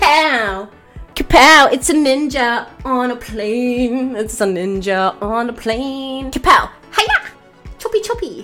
Play, download and share NINJAONAPLANE original sound button!!!!